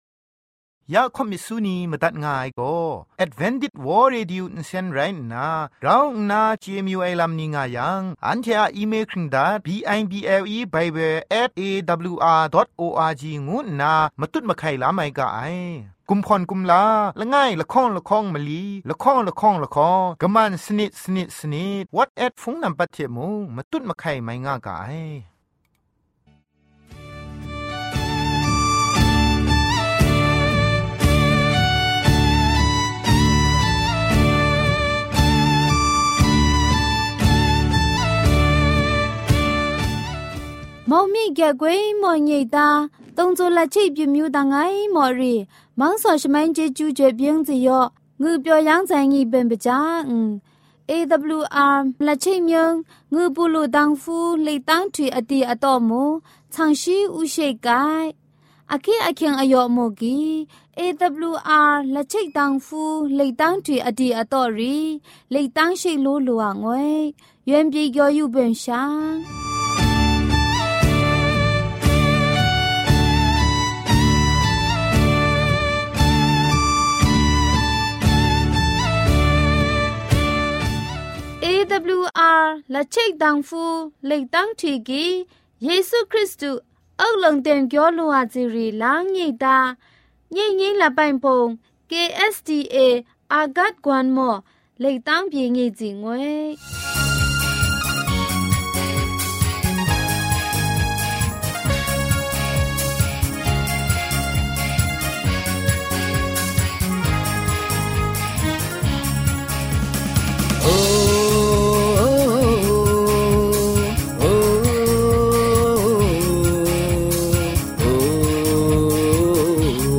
gospel song,health talk,sermon.